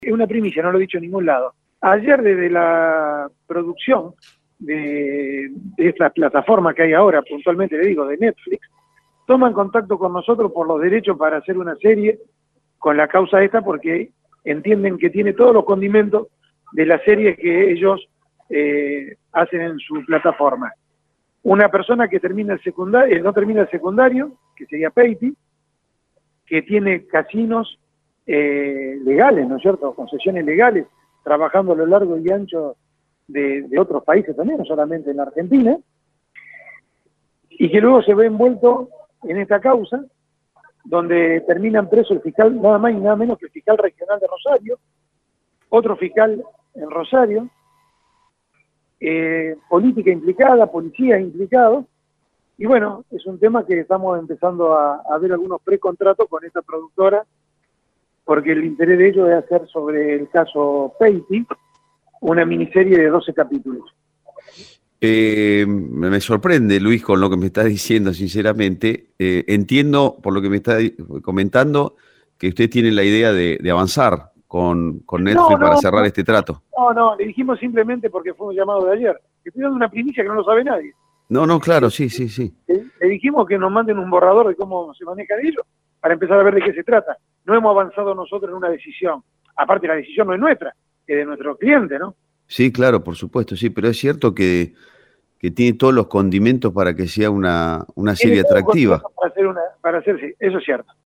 Audio Gentileza CNN Radio Rosario